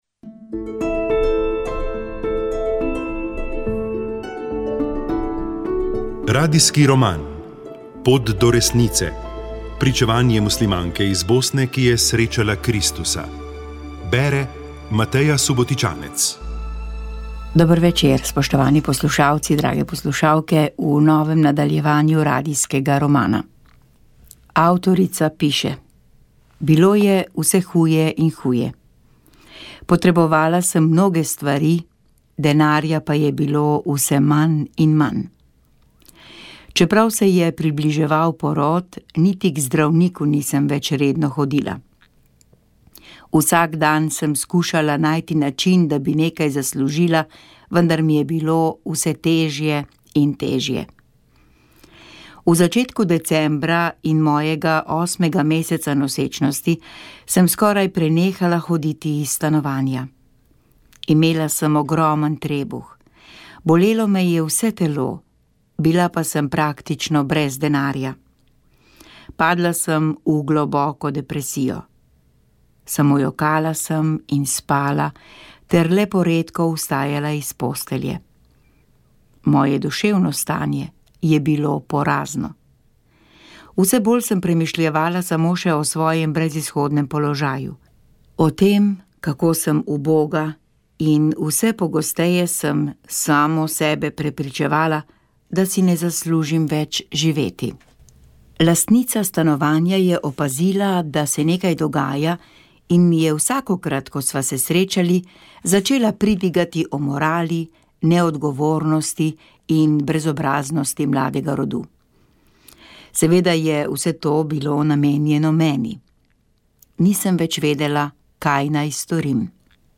Njegovo pričevanje v dveh delih je bilo posneto v letih 1978 in 1979. Dosegljivo v Arhivu Republike Slovenije.